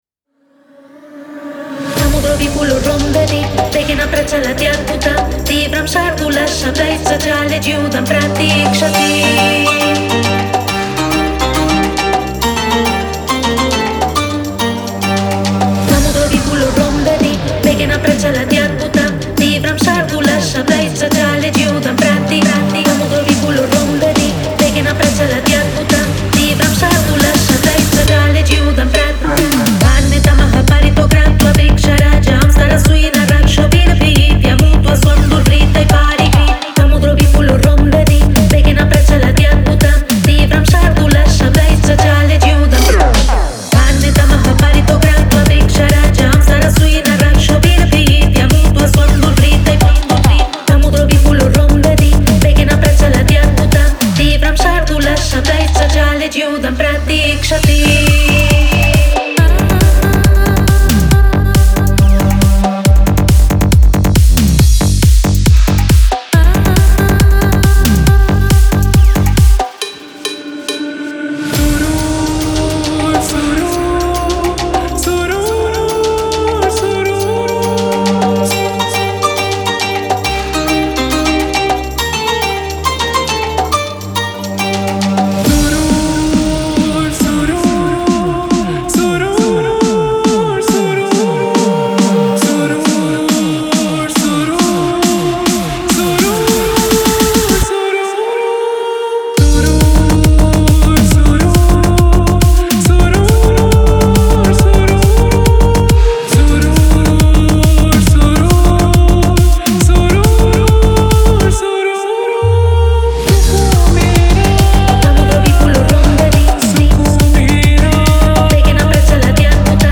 Genre:Psy Trance
このコレクションは、サンスクリット語とウルドゥー語のサンプルのユニークな融合を、エネルギッシュな138 BPMで録音したものです。
これらのボーカルは、あなたのトランスやサイケデリックトランスプロジェクトにシームレスに組み込むことができ、催眠的なリズムを注入します。
魅力的なフックから感情を引き立てる単語、即興のアドリブ、ダイナミックなチョップボーカルまで、このパックは音楽に感情を加えるための幅広いオプションを提供します。
ドライバージョンとウェットバージョンの両方が含まれており、複数の選択肢を提供します。